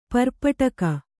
♪ parpaṭaka